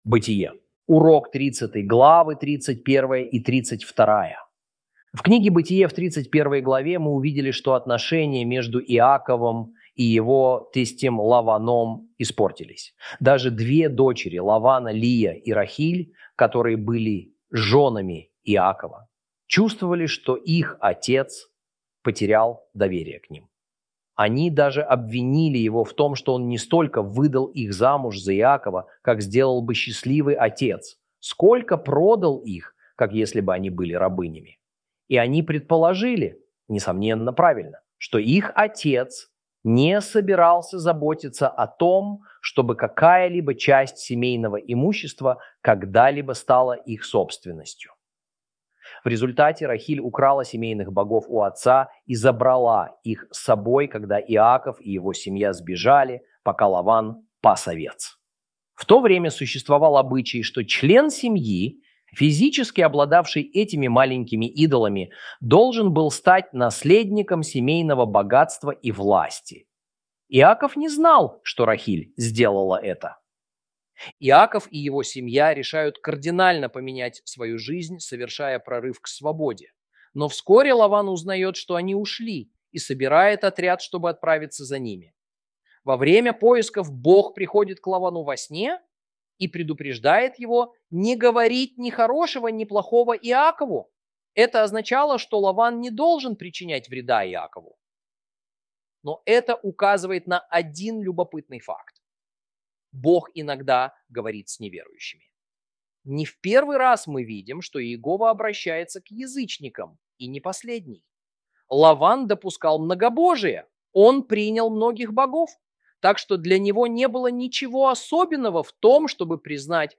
Урок 30 - Бытие́ 31 & 32 - Torah Class